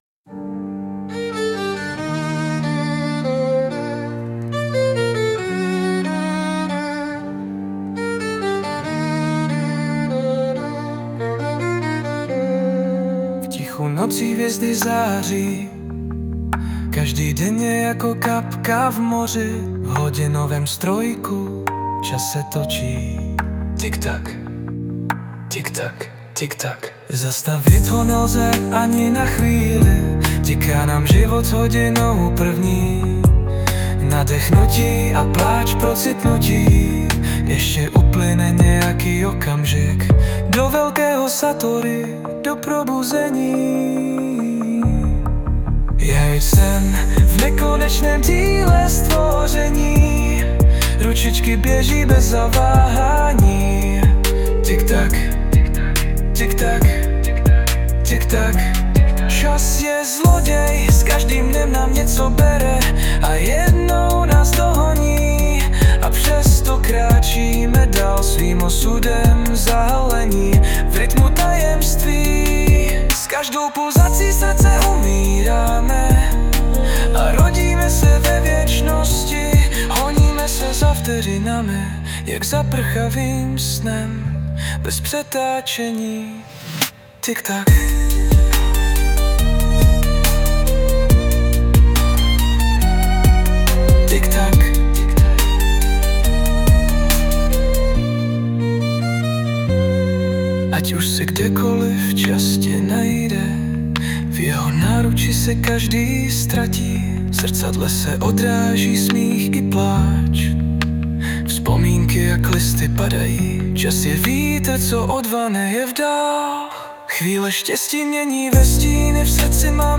2025 & Hudba, zpěv a obrázek: AI
smyčce jsou pro mé smysly jako droga - vodí si mne celou kam chtějí